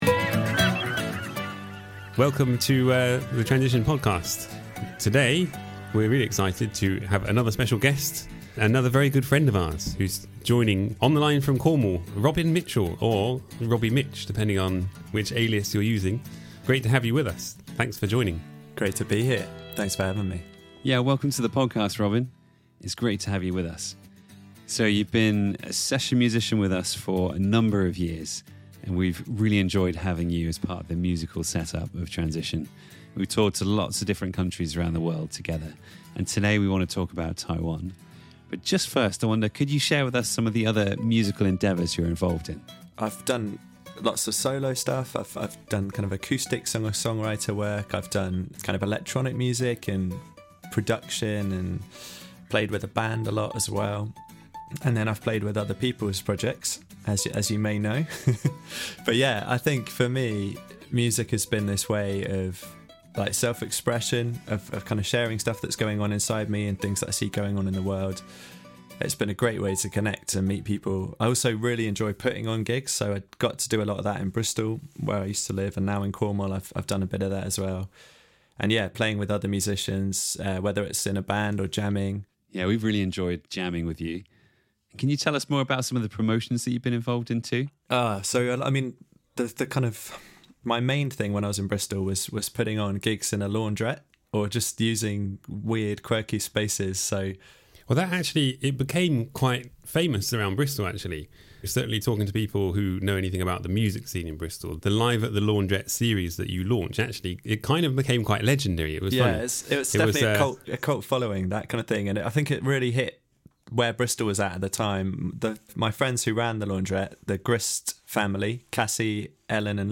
Transition Interview